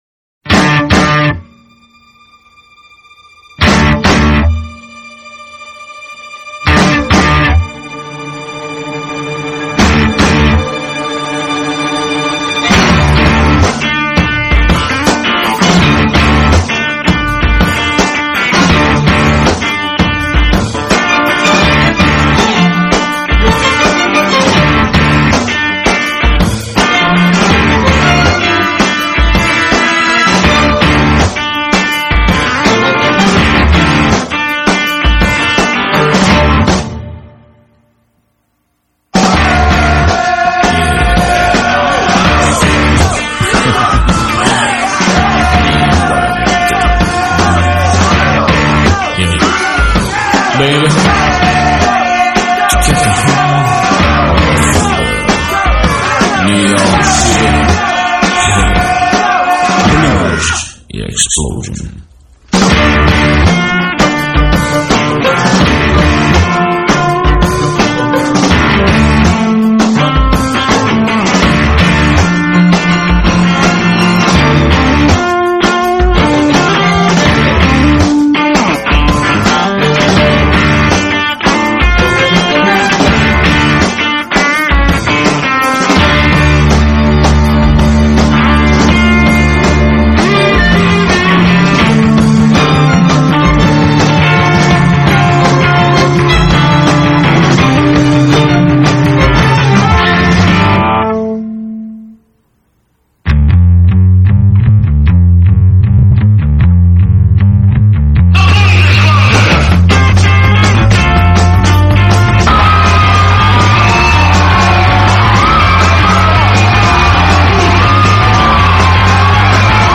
Soundtrack, Rock, Soul, Funk